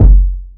kick (regular).wav